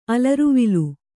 ♪ alaruvilu